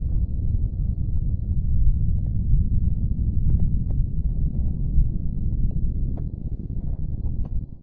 Smoothen lava sounds at loop points
env_sounds_lava.1.ogg